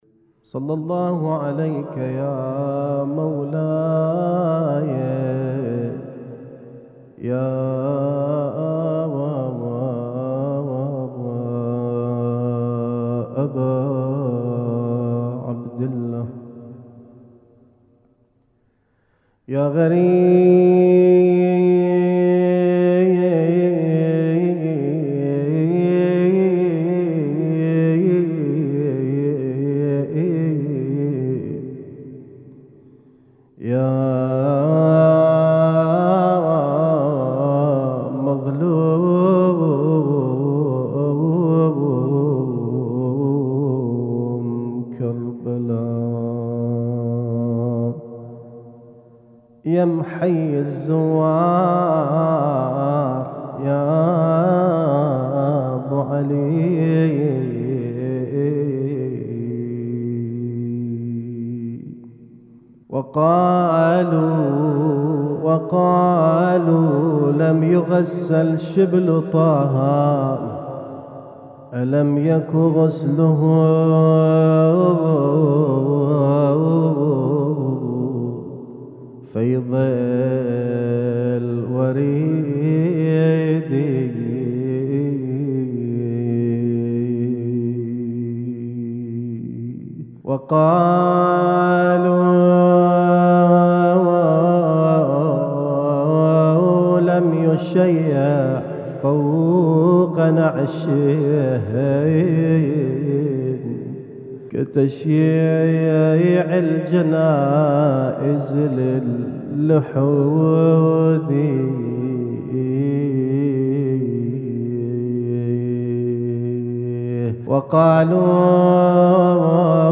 اسم التصنيف: المـكتبة الصــوتيه >> الزيارات >> الزيارات الخاصة